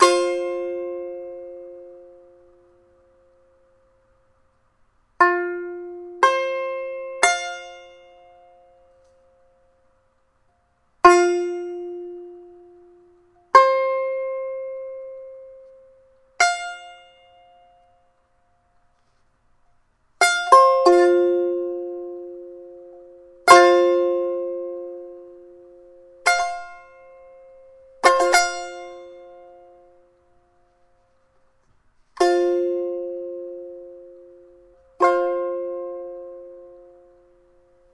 弦乐棒 " 弦乐棒6音阶条琴
描述：用蓝雪球录制的弦乐和弹拨乐，16bit
Tag: 俯仰 乱弹 strumstick 乐器 吉他 strumstick 拔毛 笔记 样品